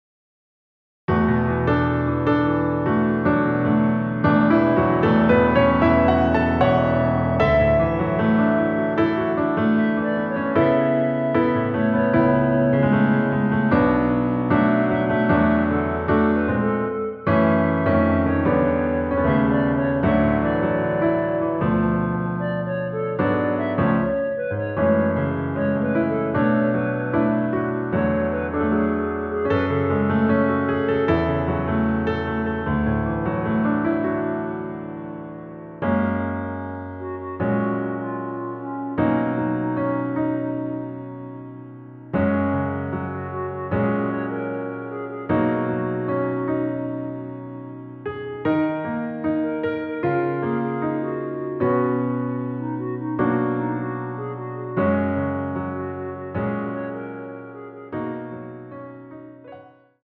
처음 시작을 후렴구로 시작을 하고 앞부분 “드릴것이 없었기에 ~ 기억도 나지 않네요” 삭제된 편곡 입니다.
원키에서(-2)내린 멜로디 포함된 MR이며 미리듣기와 본문의 가사를 참고 하세요~
F#
앞부분30초, 뒷부분30초씩 편집해서 올려 드리고 있습니다.
중간에 음이 끈어지고 다시 나오는 이유는